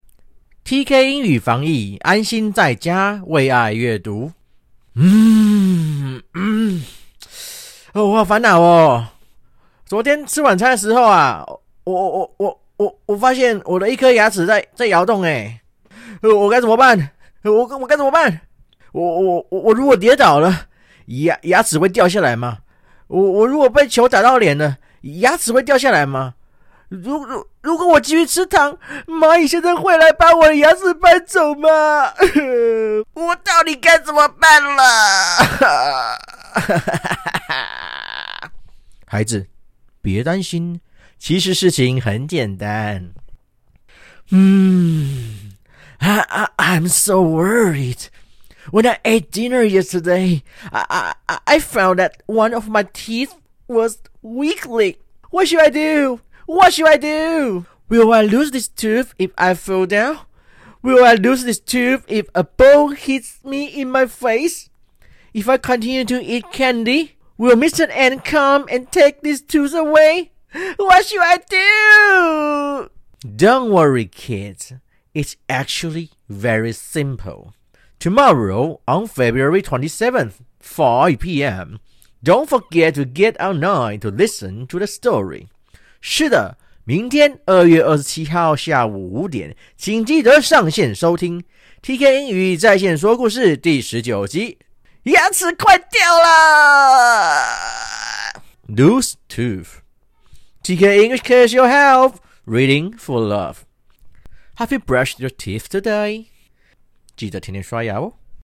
导读音频：